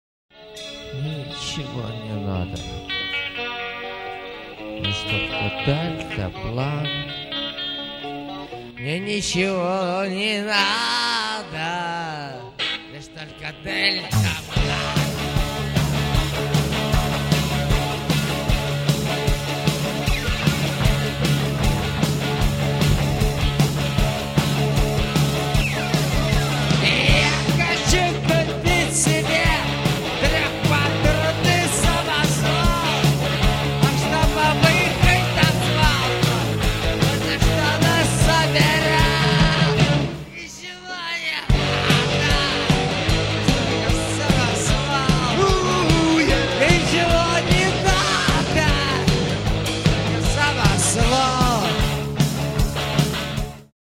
AUDIO, stereo